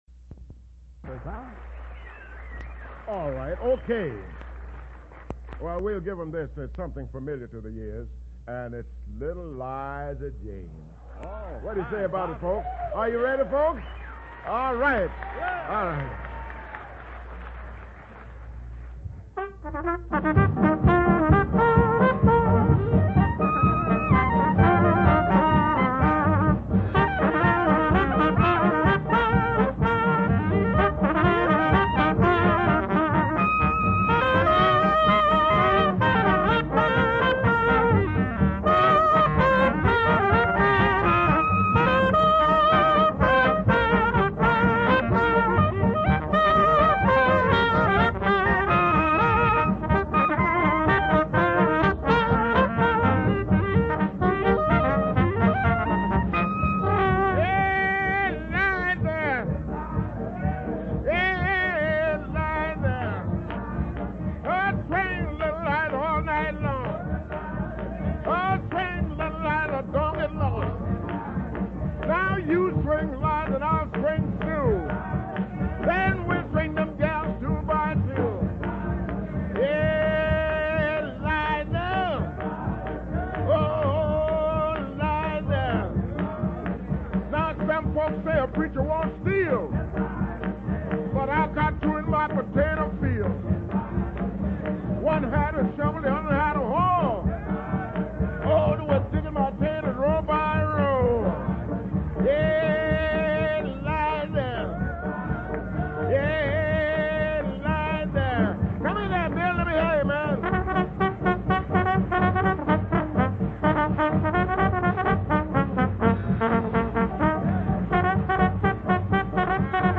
You can hear a sample from the Louisiana State Museum Jazz Collection below, with a 1917 recording of “L’il Liza Jane” by Earl Fuller’s Jazz Band.